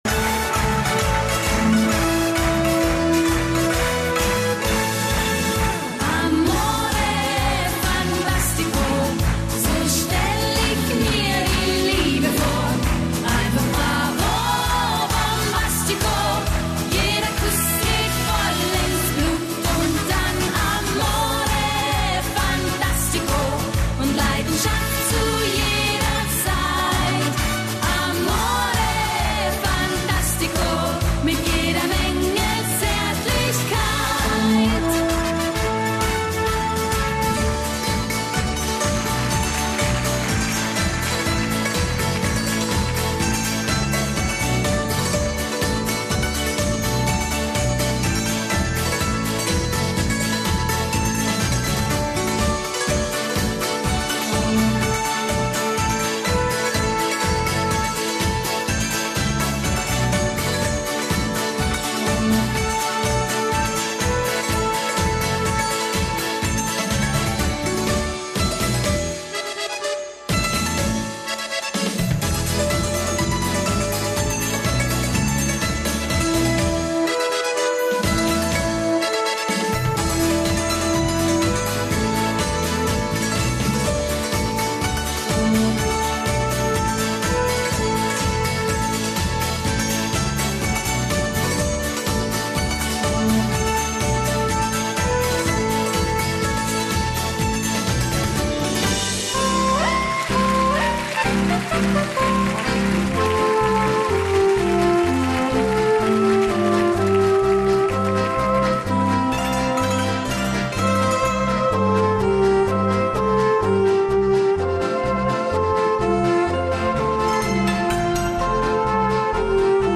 yodels
Alpine Horn, great medley of songs